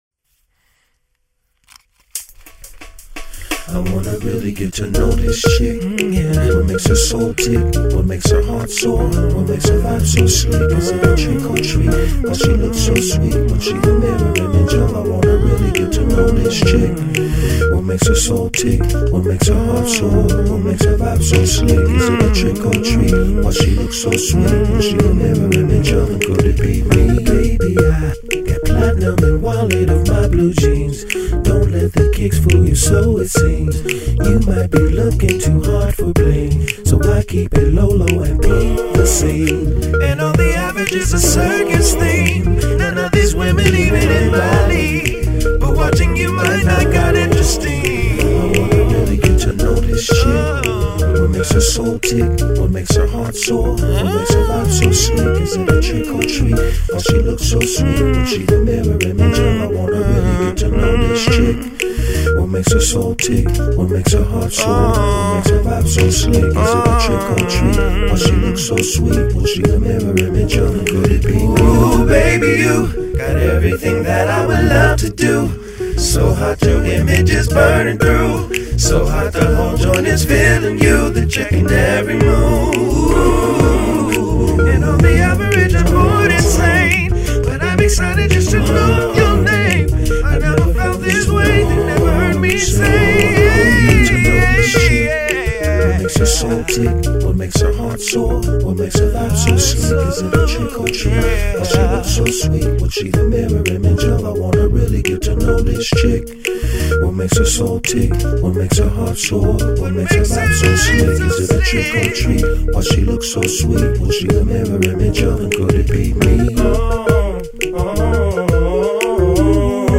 Solo artist